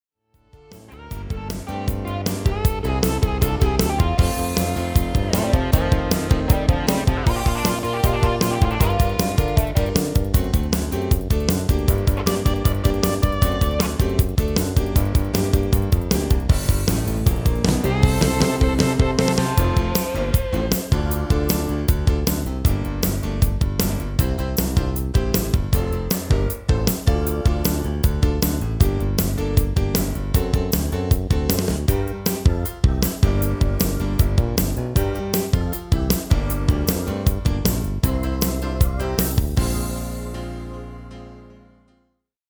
MP3-orkestband Euro 5.75